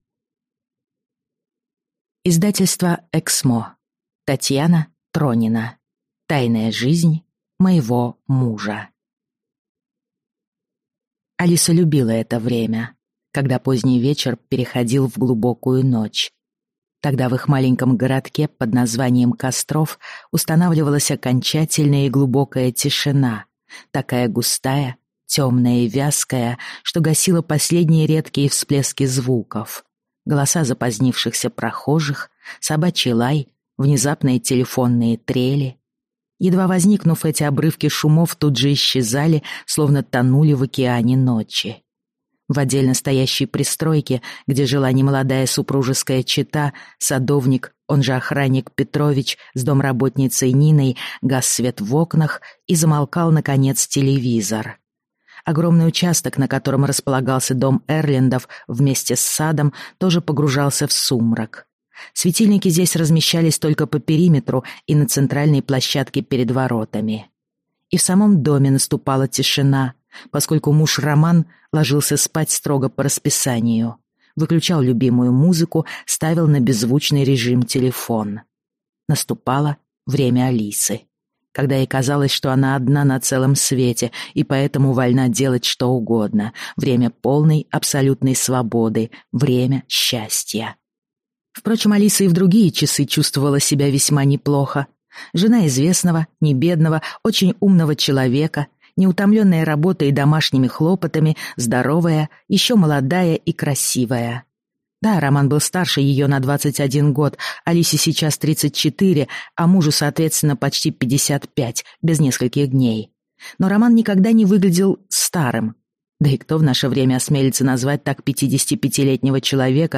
Аудиокнига Тайная жизнь моего мужа | Библиотека аудиокниг